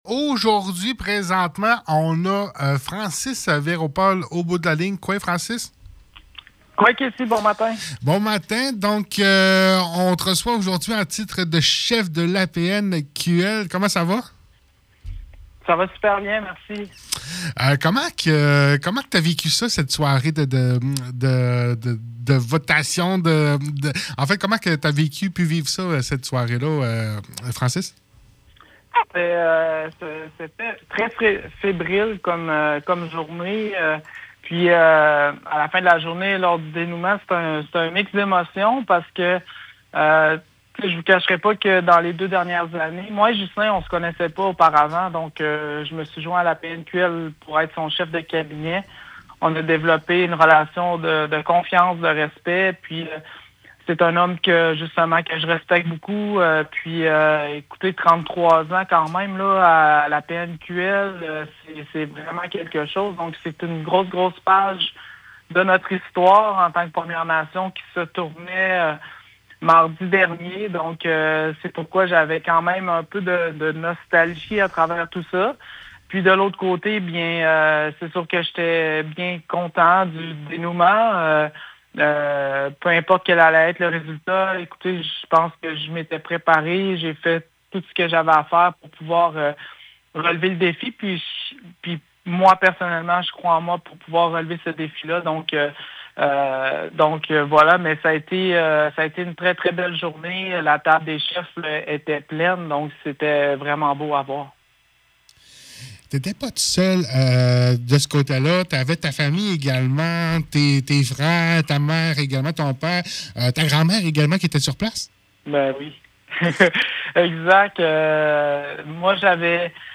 Lundi le 3 mars 2025 – Nous recevions en entrevue Francis Verreault-Paul qui nous partage son expérience et ses impression face à son nouveau poste au sein de l’APNQL.
Entrevue